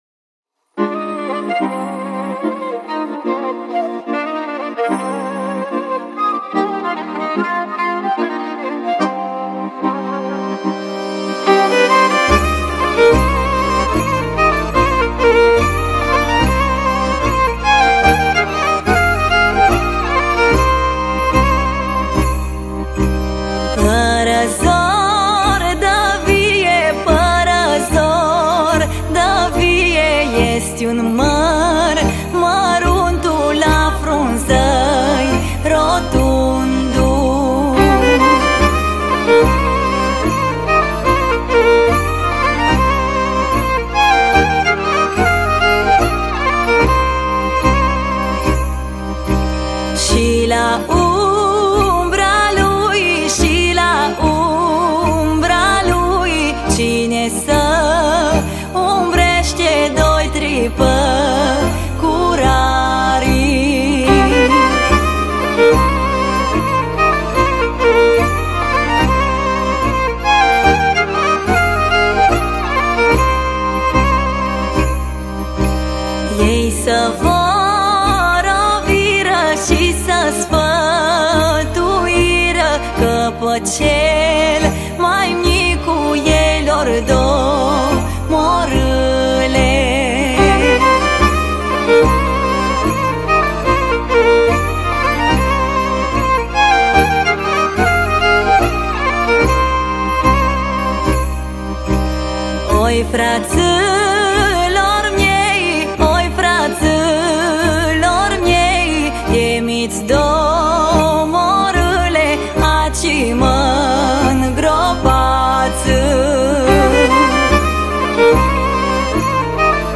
Data: 02.10.2024  Colinde Craciun Hits: 0